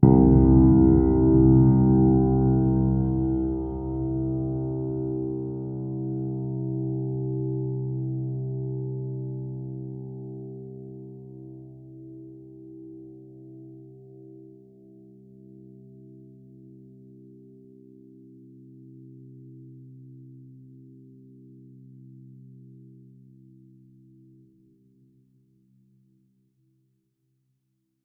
piano9.wav